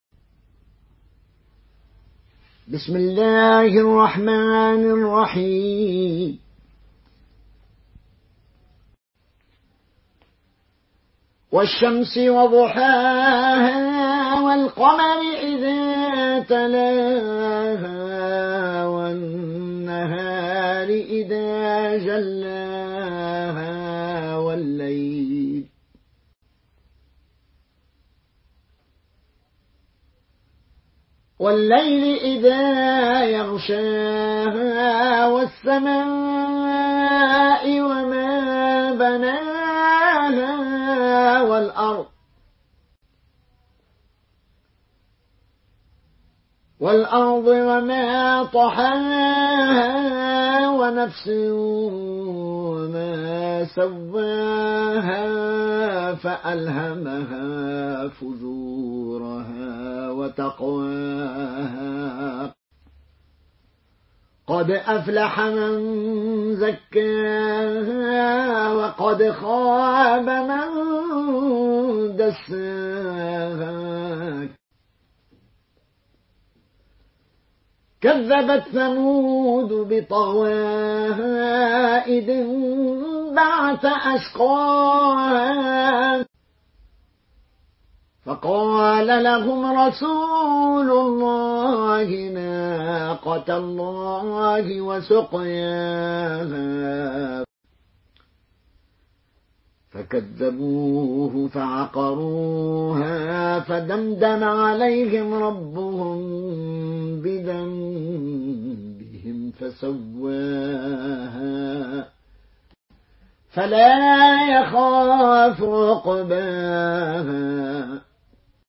مرتل قالون عن نافع